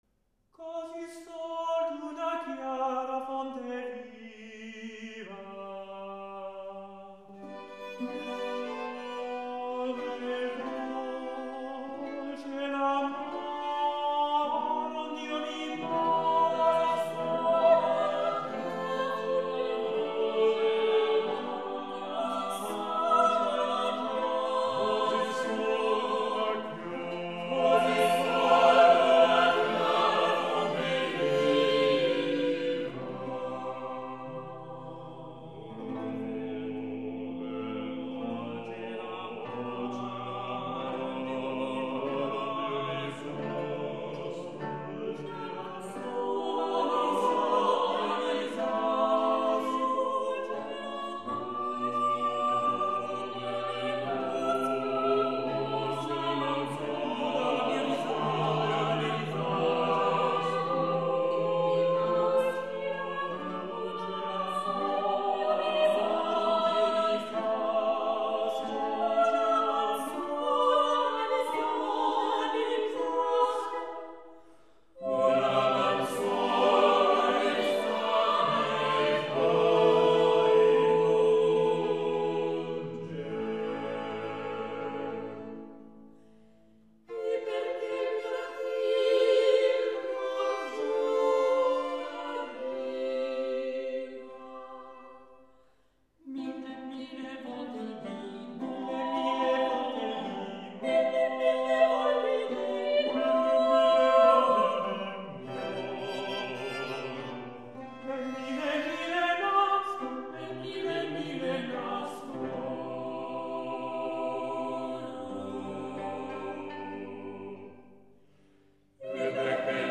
Monteverdi, Hor che 'l ciel e la terra (madrigale Libro VIII) seconda parte.mp3